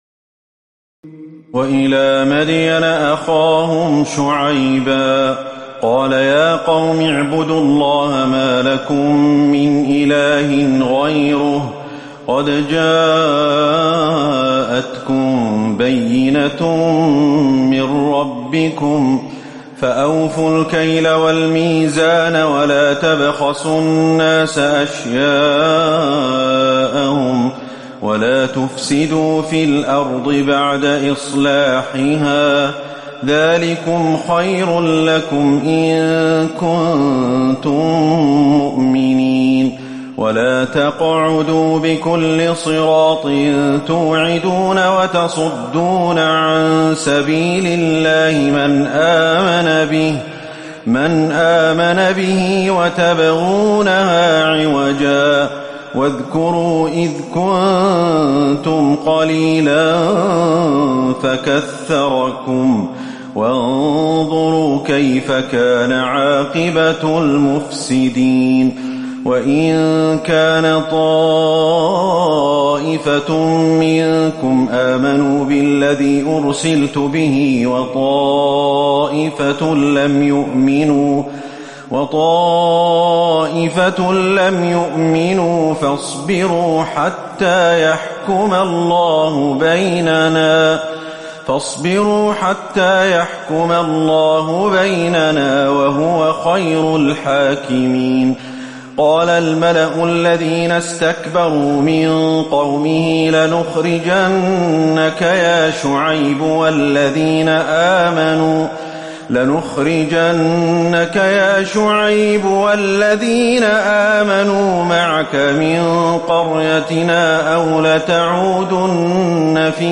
تراويح الليلة التاسعة رمضان 1438هـ من سورة الأعراف (85-171) Taraweeh 9 st night Ramadan 1438H from Surah Al-A’raf > تراويح الحرم النبوي عام 1438 🕌 > التراويح - تلاوات الحرمين